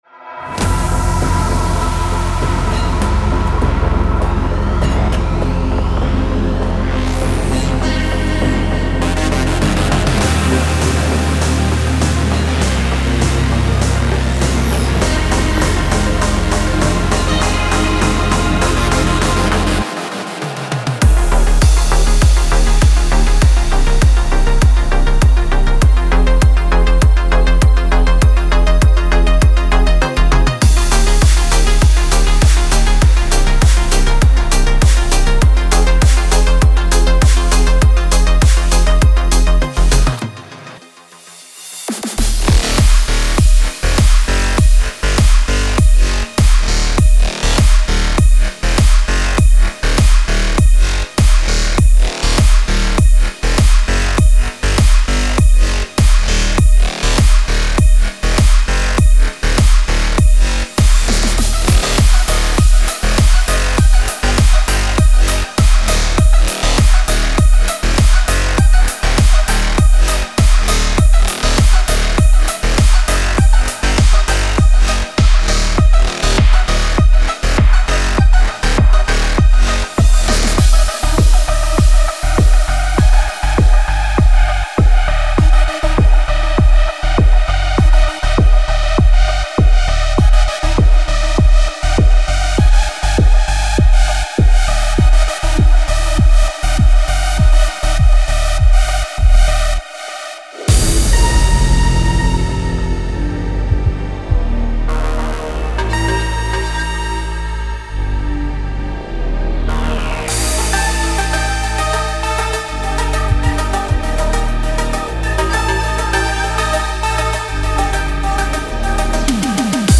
Type: Serum Midi Samples
Chill Out / Lounge Industrial Multi-genre Vocals
Atmospheres & Glitches
• Subtle ring modulation fosters a haunted, whispery swirl.